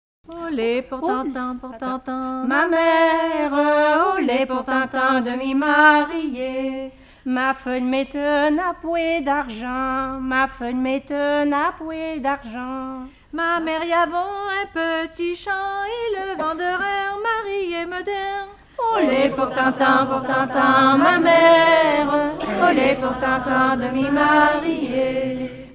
en duo
Genre dialogue
Pièce musicale inédite